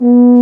BRS F TUBA0C.wav